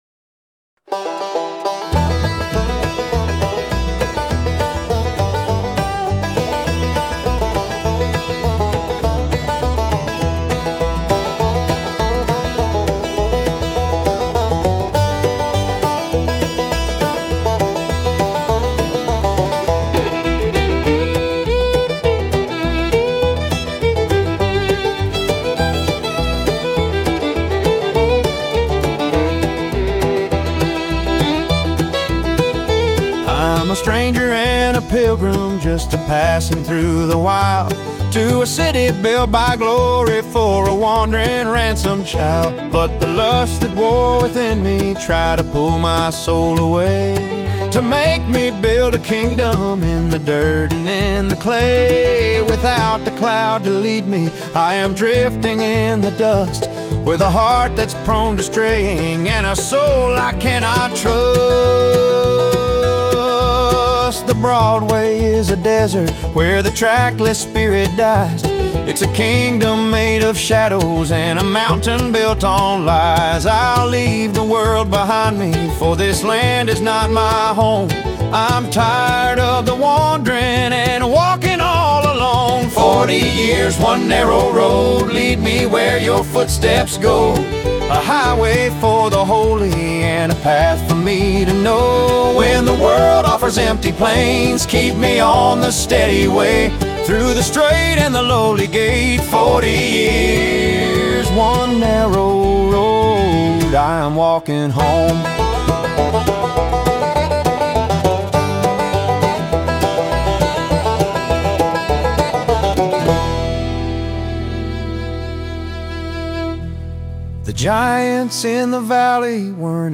Contemporary Christian